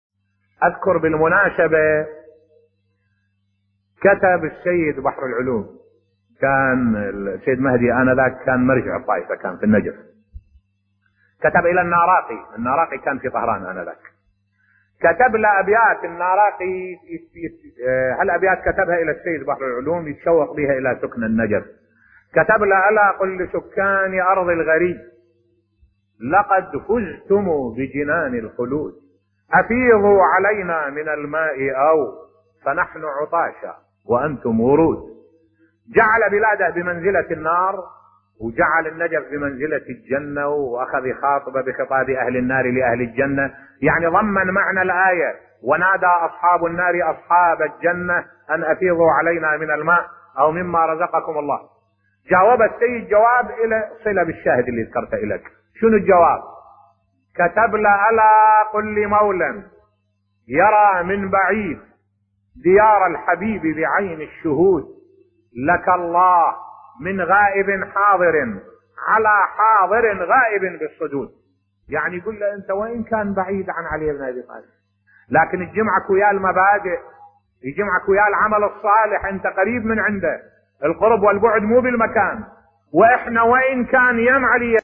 ملف صوتی قصة السيد بحر العلوم والعلامة النراقي بصوت الشيخ الدكتور أحمد الوائلي